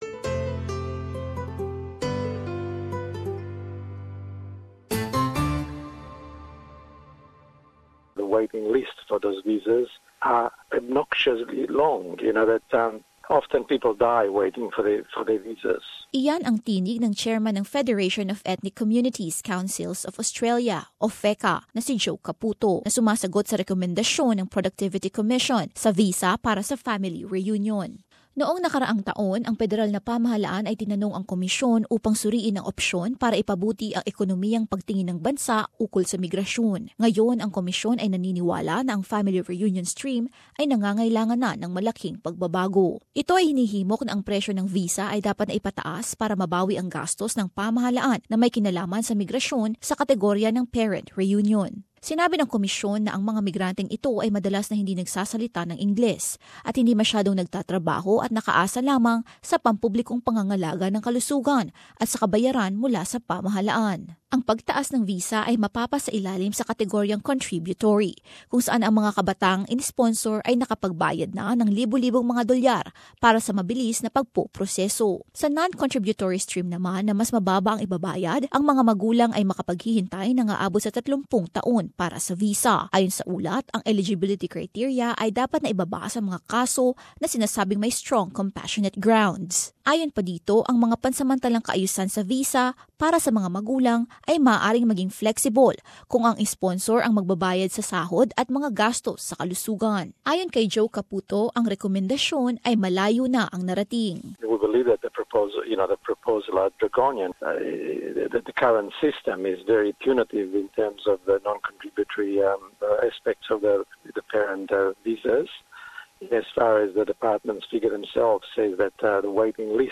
But, as this report shows, some are challenging a recommended overhaul of the family-reunion and skilled-migrant streams, saying the existing conditions can just be improved.